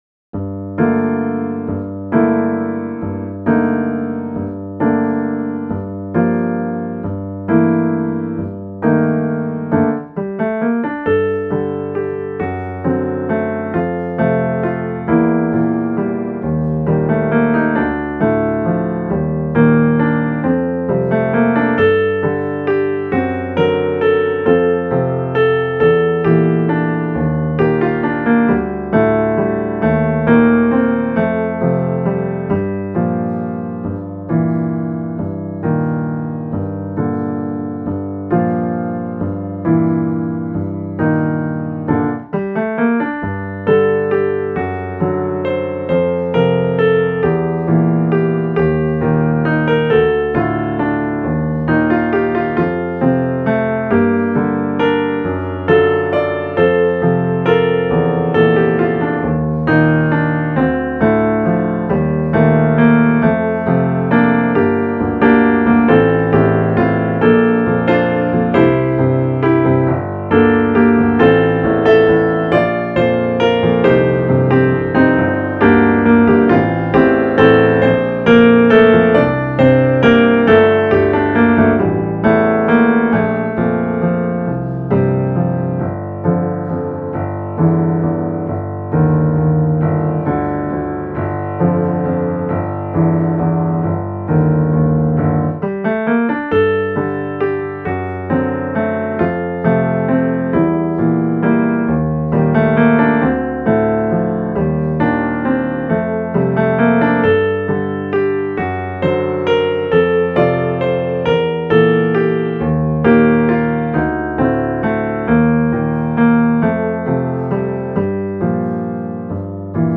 ミステリアスで怪しく暗いピアノソロ曲です。
♩=free